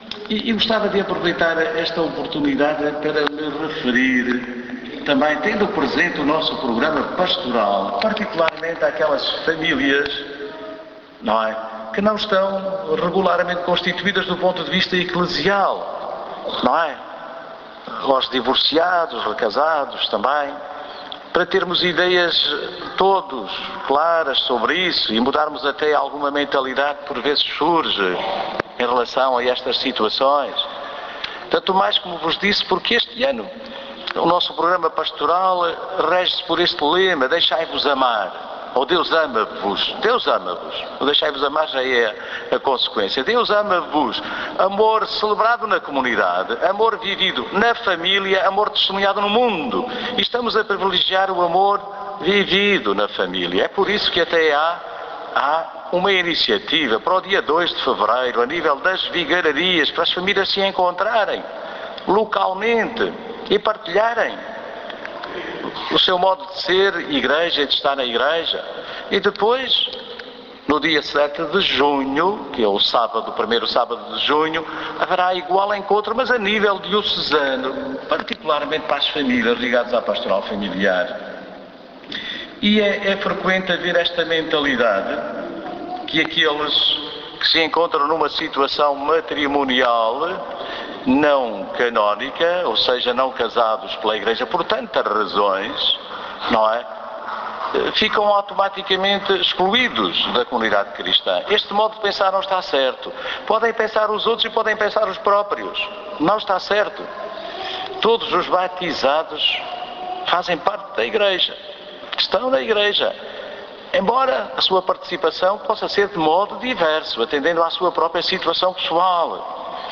O anúncio foi deixado ontem por D. Manuel Quintas, no encerramento da visita pastoral às paróquias da cidade de Portimão, num ano, particularmente, dedicado à família pela Diocese do Algarve.